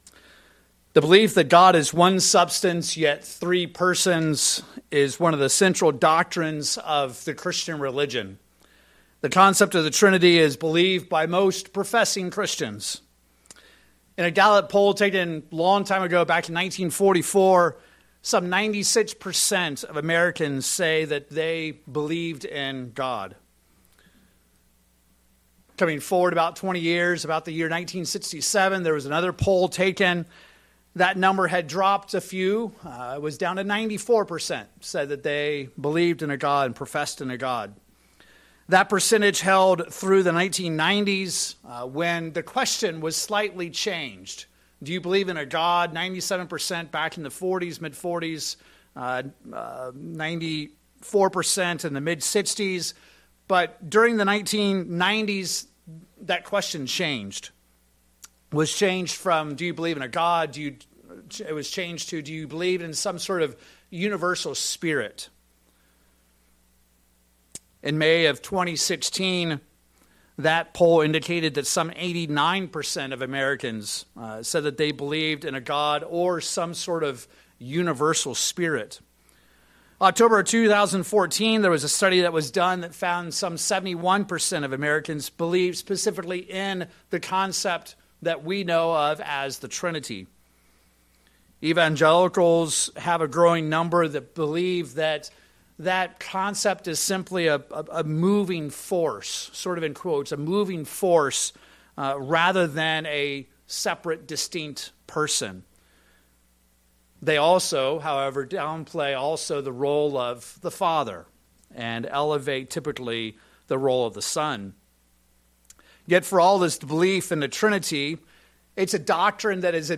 In this sermon we will learn that at the center of the trinity debate is a great misunderstanding of who Jesus was and is.